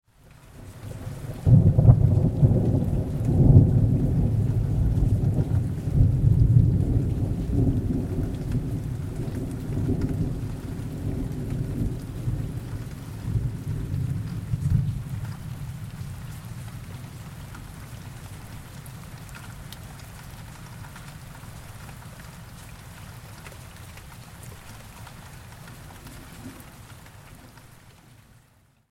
دانلود آهنگ طوفان 12 از افکت صوتی طبیعت و محیط
دانلود صدای طوفان 12 از ساعد نیوز با لینک مستقیم و کیفیت بالا
جلوه های صوتی